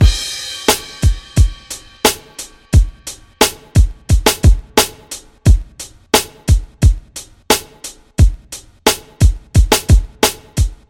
描述：原声
Tag: 88 bpm Acoustic Loops Drum Loops 1.84 MB wav Key : Unknown